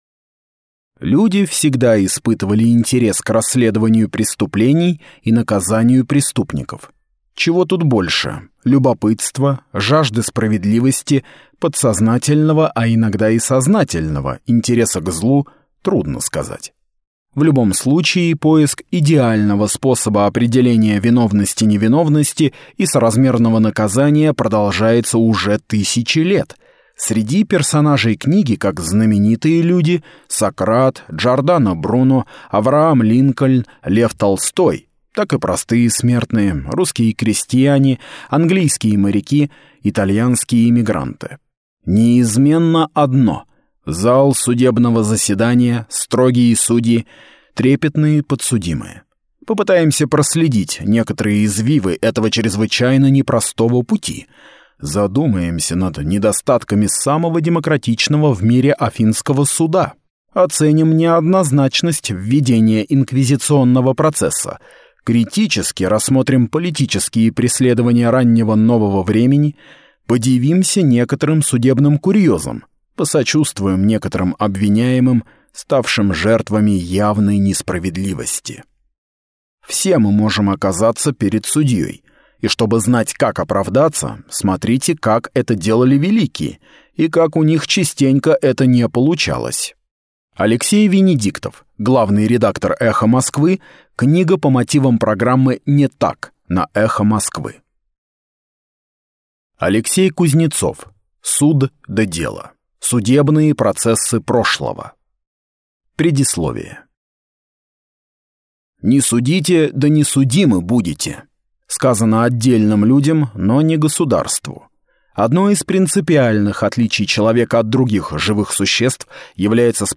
Аудиокнига Суд да дело. Судебные процессы прошлого | Библиотека аудиокниг